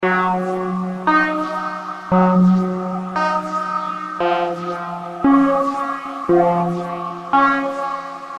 Ambient-loop-115-bpm.mp3